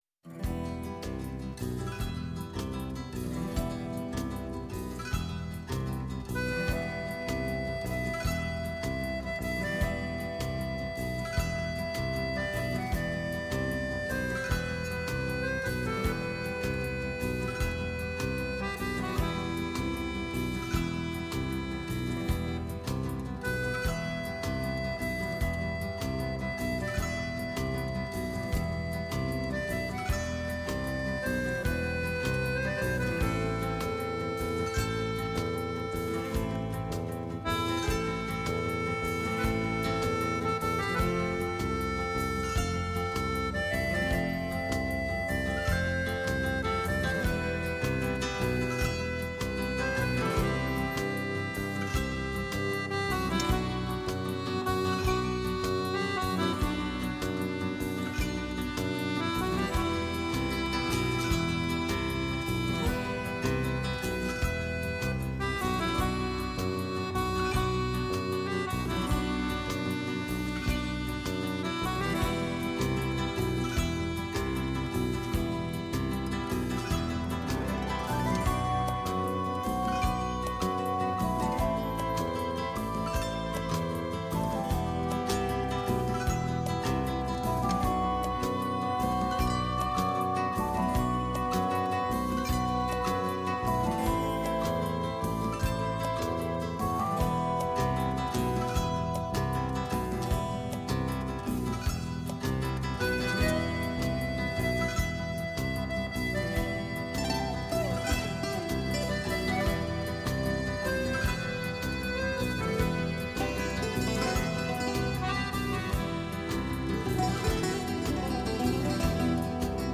σε μια συζήτηση γύρω από τα τραγούδια της και το συγγραφικό της έργο, καθώς και για το αποτύπωμα που άφησε στη μουσική ο Διονύσης Σαββόπουλος